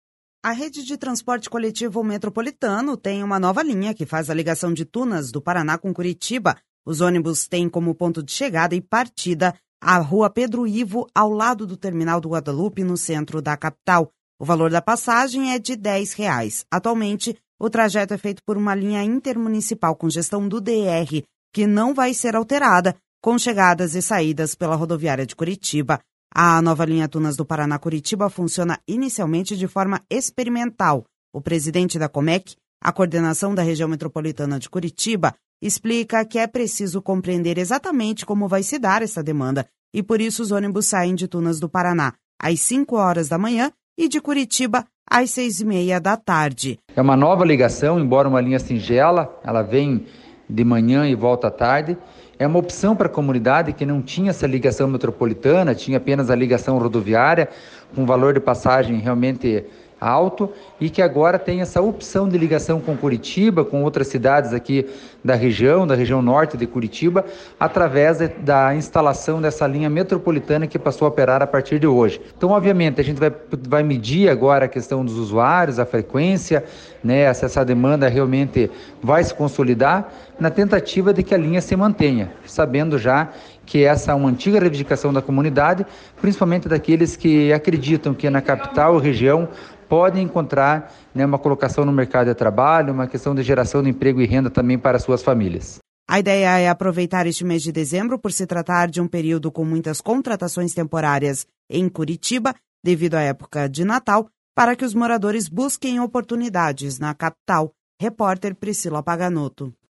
O presidente da Comec, a Coordenação da Região Metropolitana de Curitiba, explica que é preciso compreender exatamente como vai se dar esta demanda e por isso, os ônibus saem de Tunas do Paraná às cinco horas da manhã e de Curitiba às seis e meia da tarde.// SONORA GILSON SANTOS// A ideia é aproveitar este mês de dezembro, por se tratar de um período com muitas contratações temporárias em Curitiba, devido a época de Natal, para que os moradores busquem oportunidades na Capital.